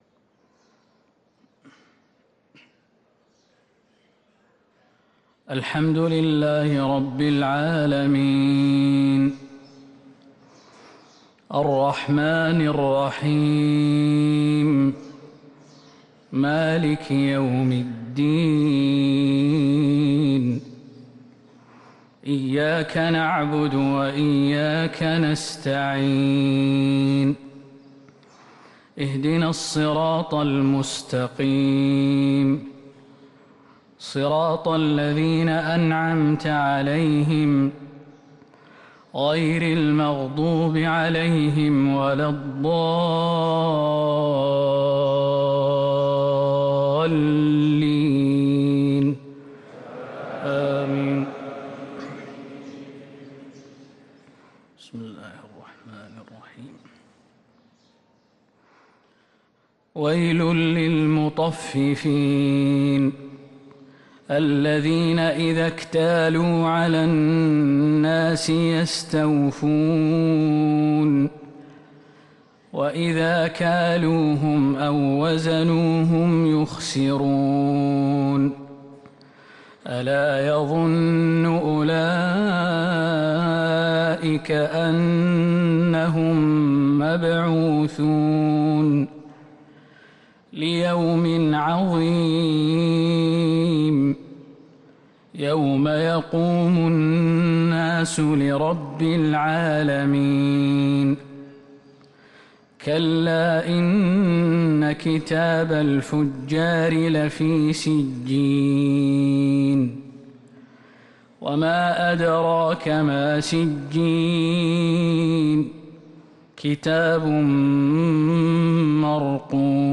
صلاة الفجر للقارئ خالد المهنا 10 شعبان 1444 هـ
تِلَاوَات الْحَرَمَيْن .